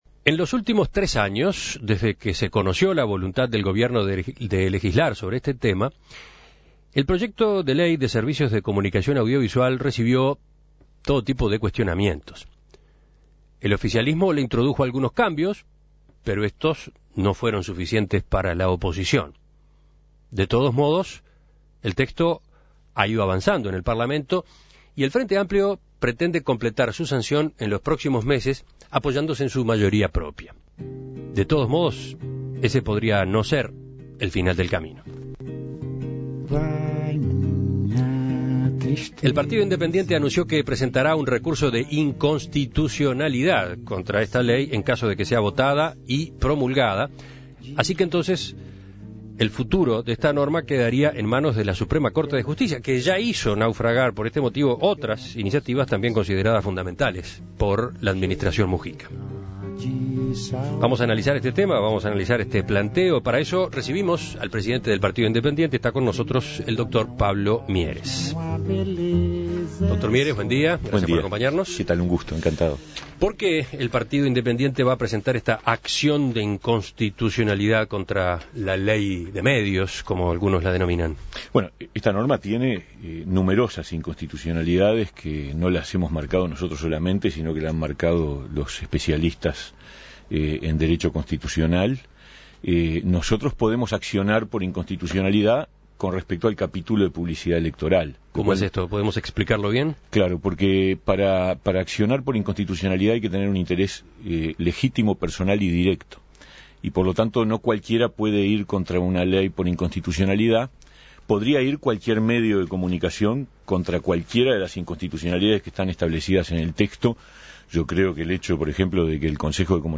El Partido Independiente (PI) resolvió presentar un recurso de inconstitucionalidad en caso de que se apruebe la Ley de Medios. Desde el partido alegan que los artículos referidos a la publicidad electoral no tratan de la misma manera a las agrupaciones políticas que se medirán en octubre. A propósito, En Perspectiva entrevistó al presidente del PI Pablo Mieres.